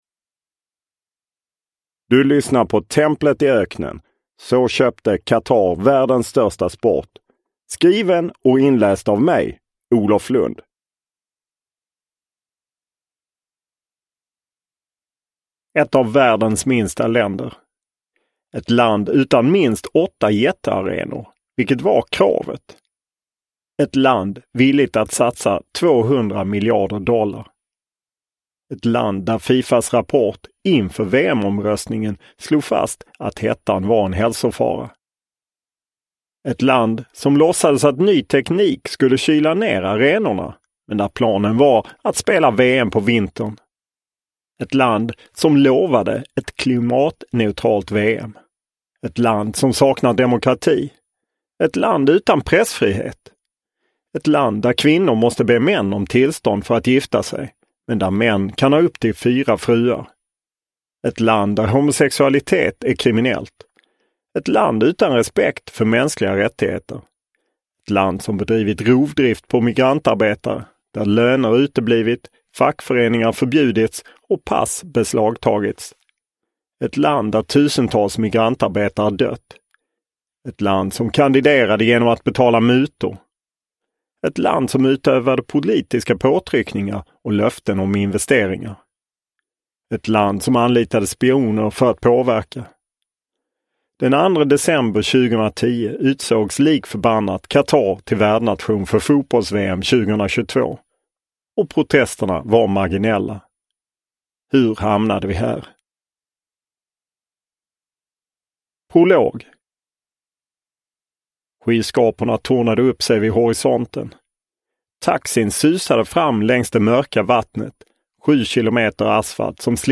Templet i öknen – Ljudbok – Laddas ner